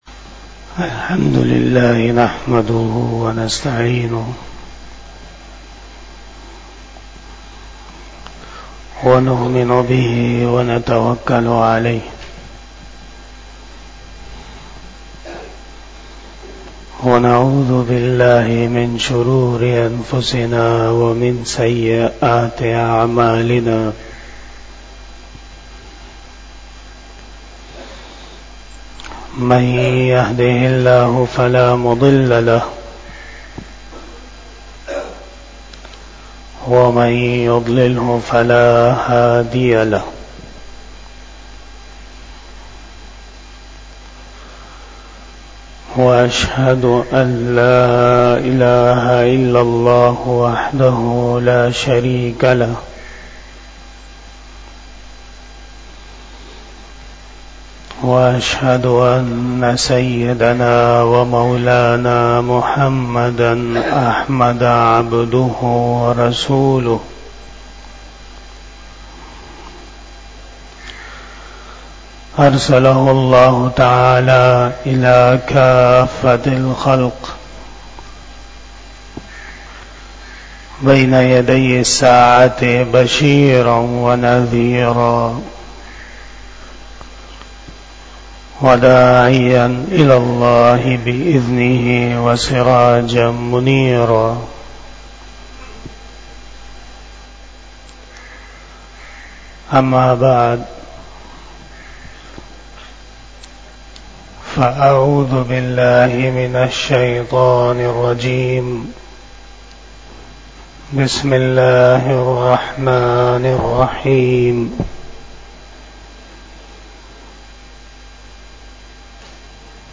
50 Bayan E Jummah 15 December 2023 (01 Jamadi Us Sani 1445 HJ)